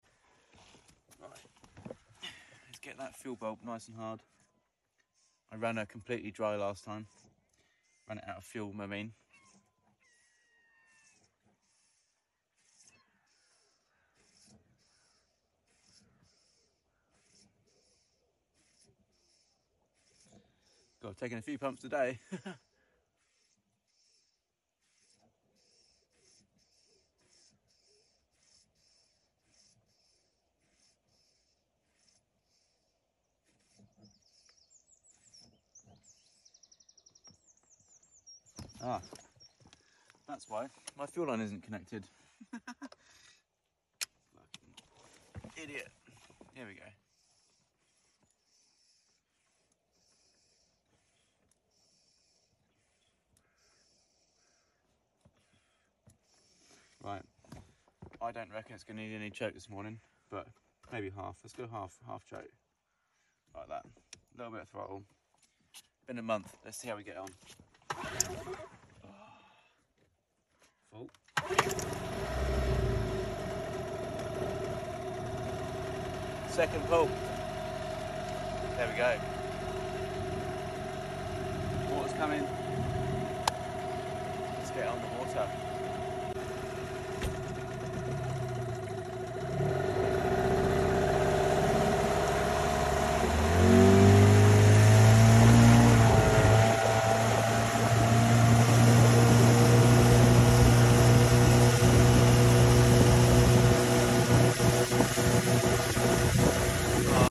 Yammy 2 stroke 15 startup sound effects free download
Yammy 2 stroke 15 startup vid! Out of practice, forgot to hook up the fuel line!